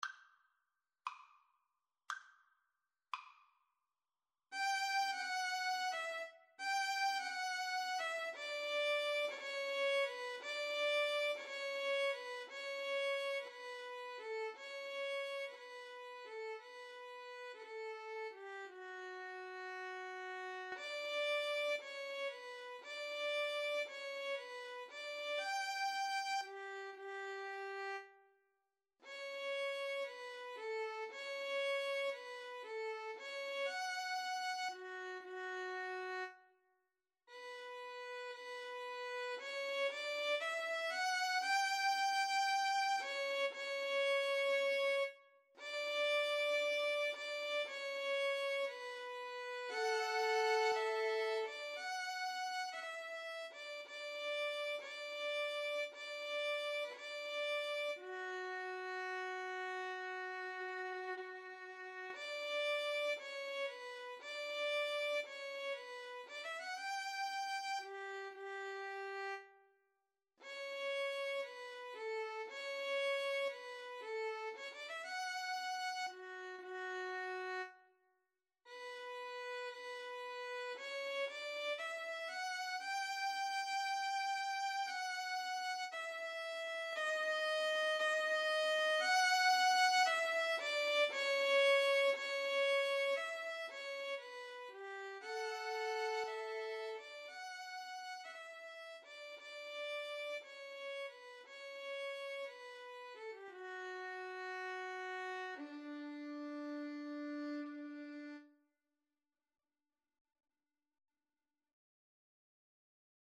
6/8 (View more 6/8 Music)
.=58 Andante con moto (View more music marked Andante con moto)
B minor (Sounding Pitch) (View more B minor Music for Violin Duet )
Violin Duet  (View more Intermediate Violin Duet Music)
Classical (View more Classical Violin Duet Music)